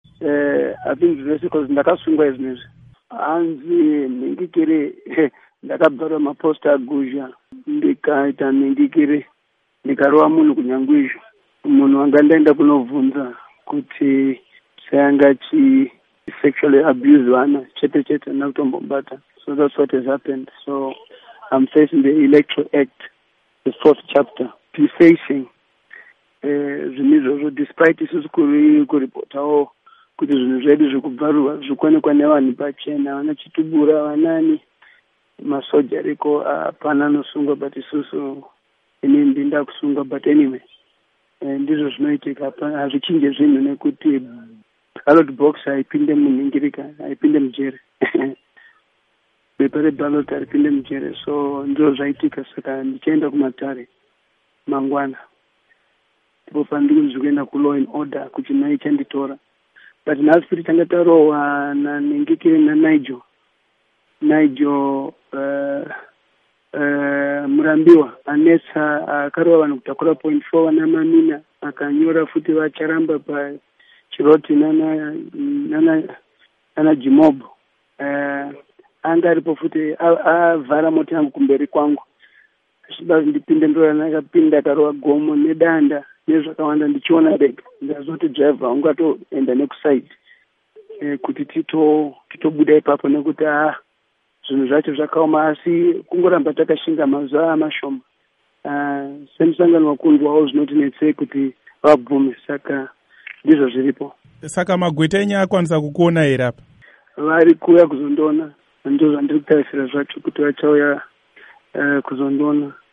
Hurukuro naVaTemba Mliswa